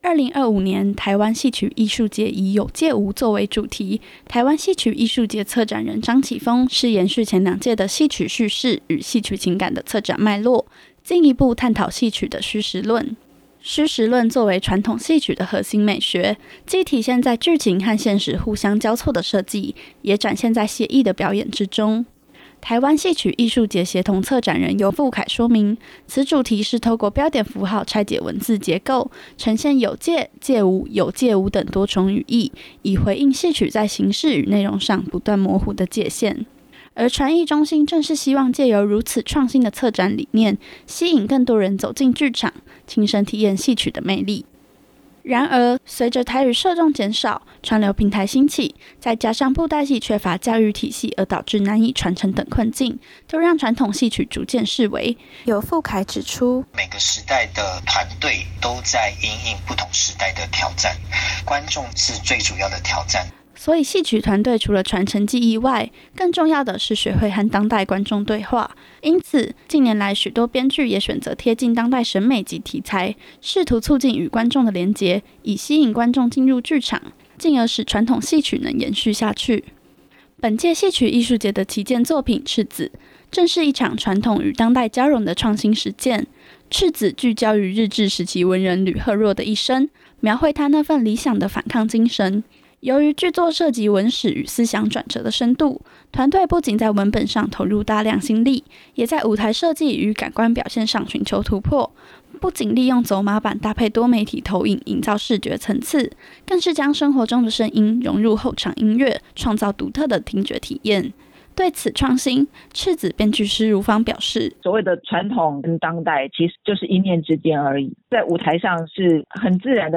的專題報導】